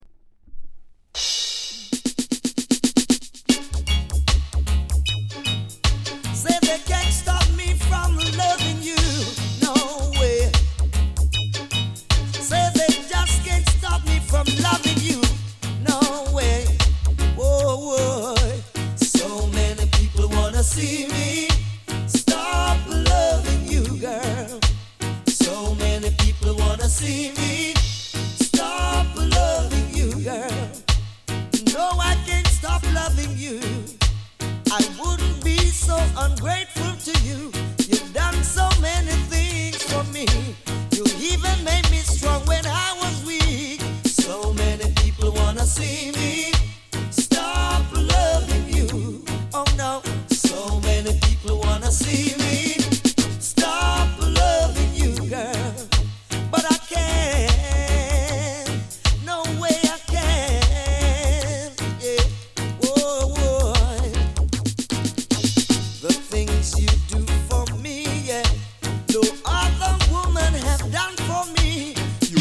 高品質 90s 唄もの *